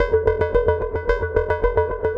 Index of /musicradar/rhythmic-inspiration-samples/110bpm
RI_ArpegiFex_110-01.wav